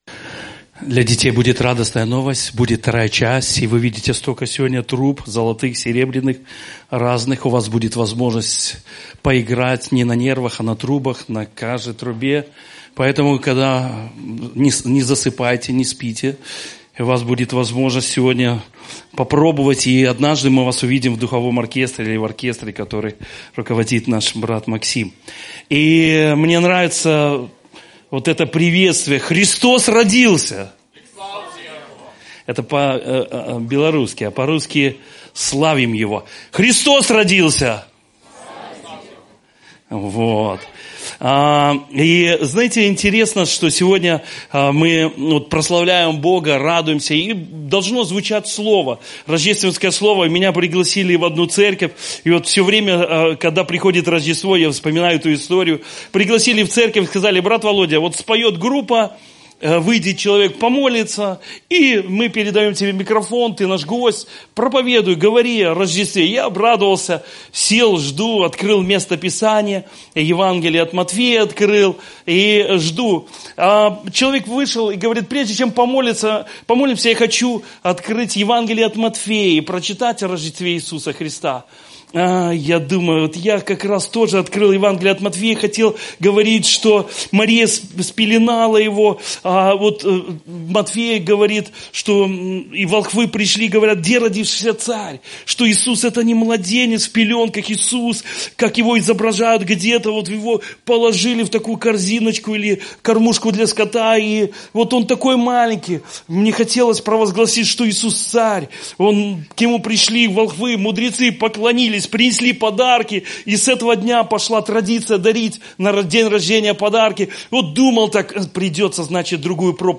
Пропаведзі